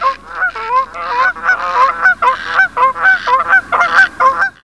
geese2.wav